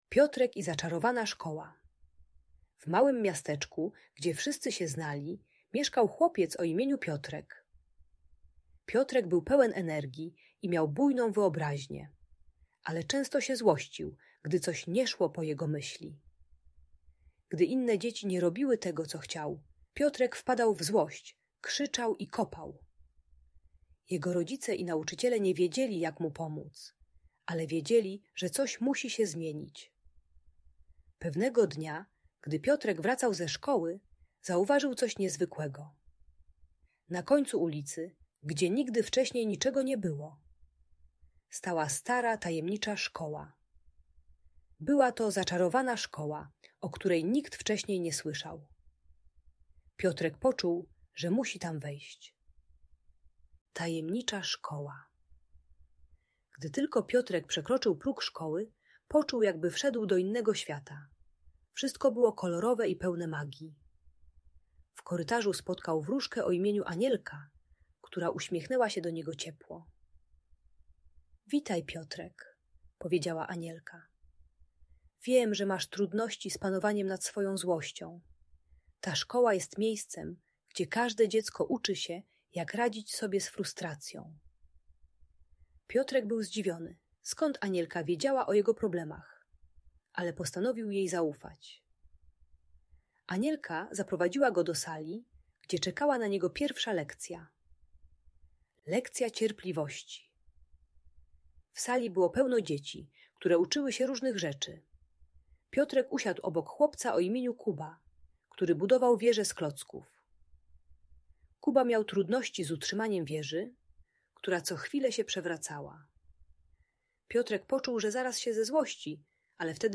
Historia Piotrka uczy techniki głębokiego oddechu oraz współpracy zamiast agresji. Audiobajka o radzeniu sobie z frustracją, gdy coś nie idzie po myśli dziecka.